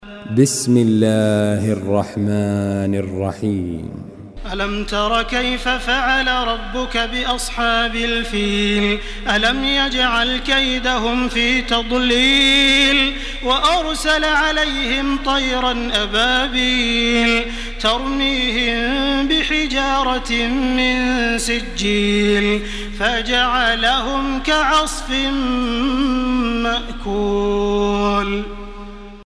تراويح الحرم المكي 1429
مرتل